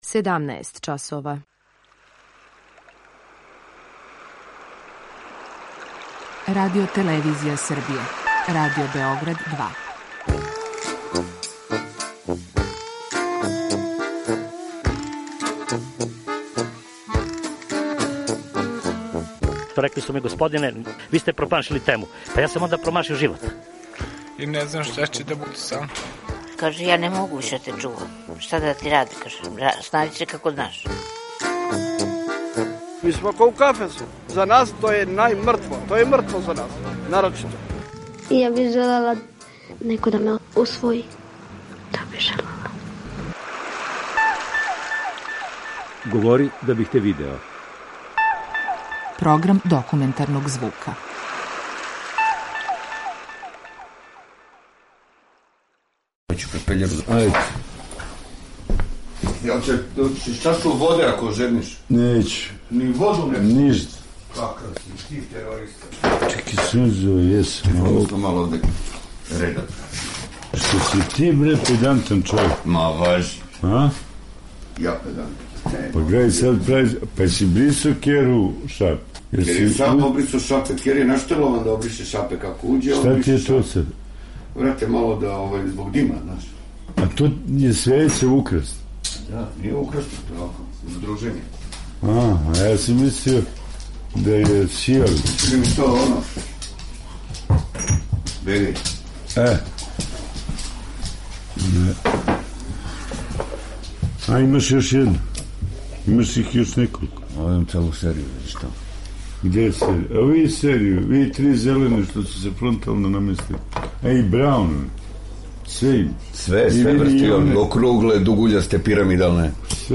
Документарни програм
О стрпљењу, лепоти живота, доношењу одлика говори човек из околине.